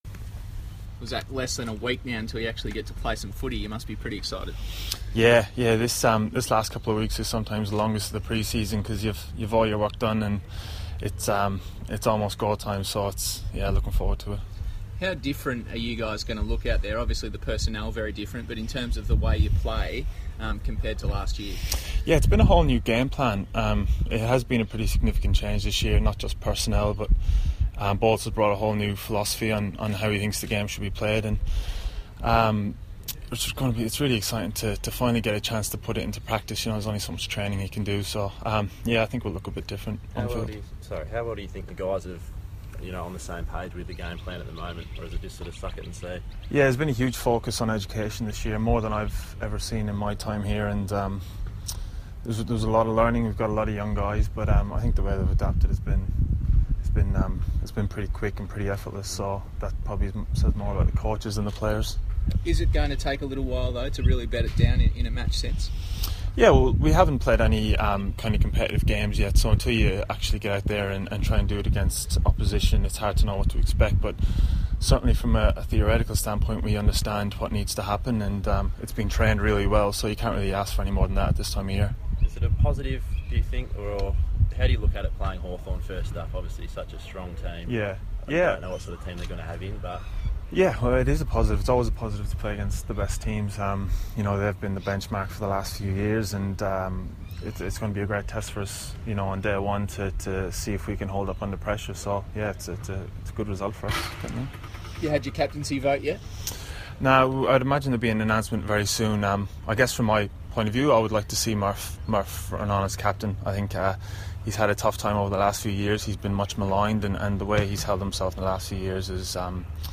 Zach Tuohy press conference - February 12
Carlton defender Zach Tuohy faces the media at Ikon Park ahead of Friday's training session.